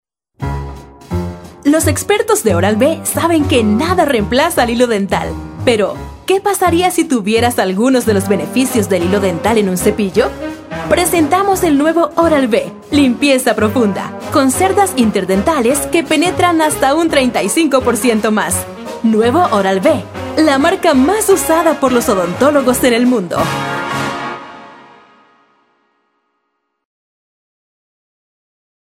Locutora Comercial, locutora de Noticias, editora y productora de radio.
Sprechprobe: Industrie (Muttersprache):